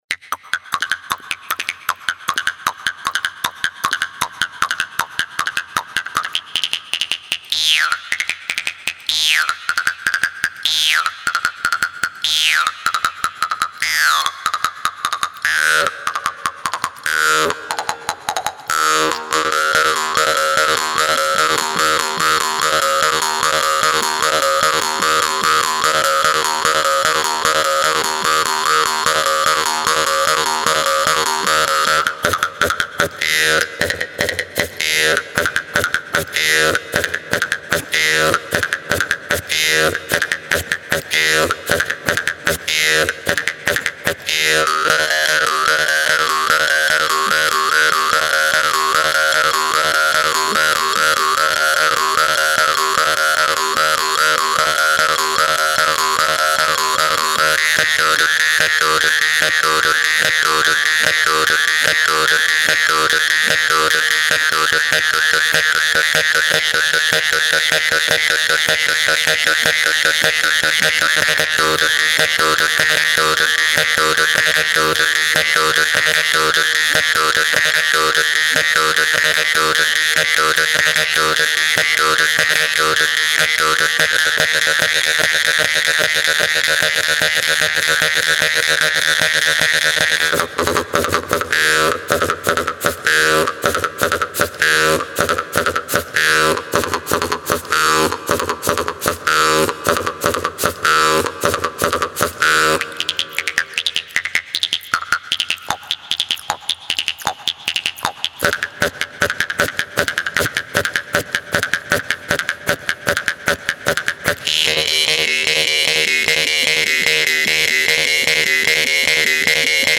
Jew's-Harp
Genre: Electronic.